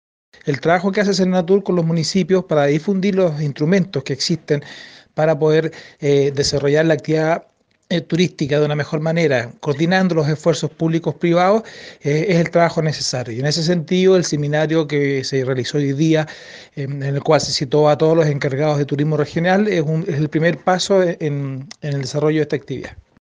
Javier-Puiggros-Seremi-de-Economía-Fomento-y-Turismo.mp3